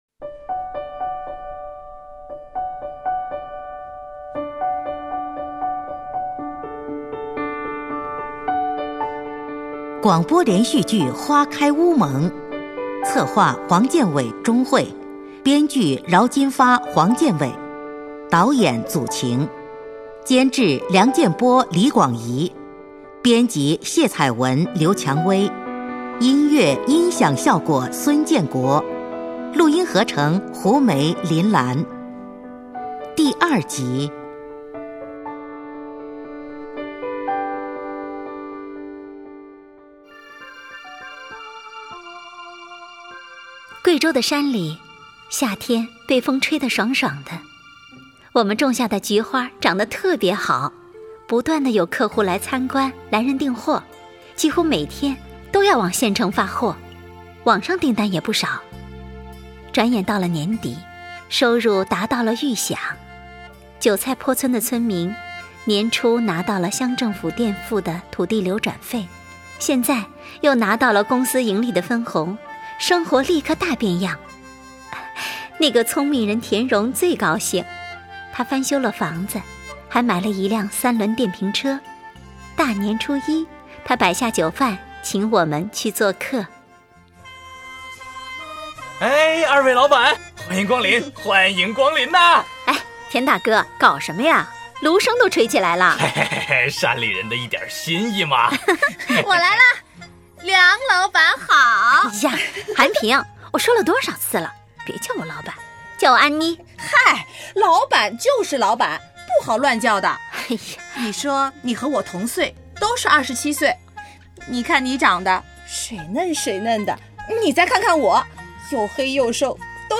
广播连续剧《花开乌蒙》第二集